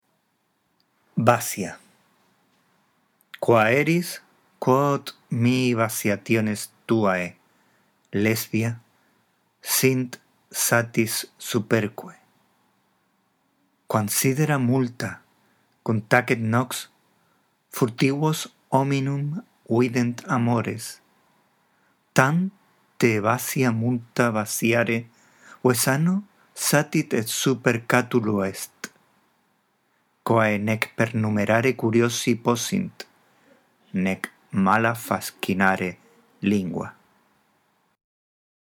Esta audición del texto te guiará en la práctica de la lectura